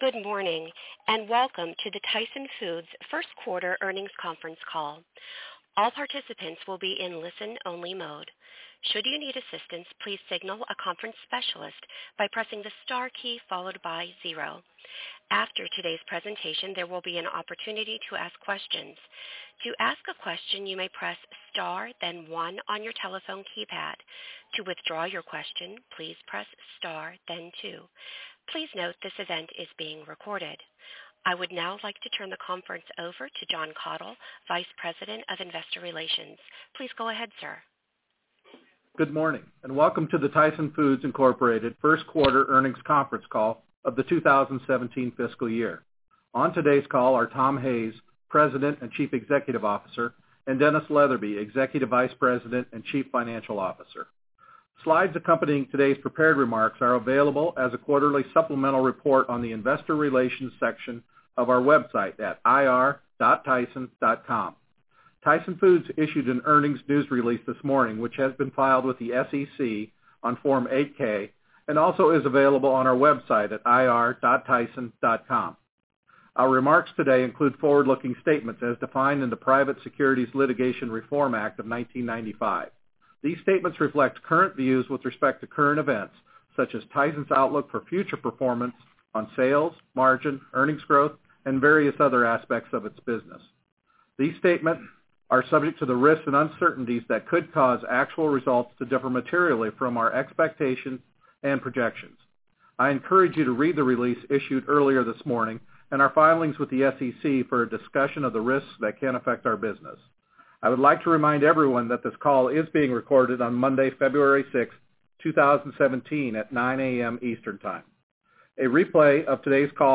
Tyson Foods Inc. - Q1 2017 Tyson Foods Earnings Conference Call